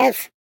Sfx_tool_spypenguin_vo_hit_wall_04.ogg